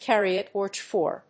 アクセントcárry a tórch for…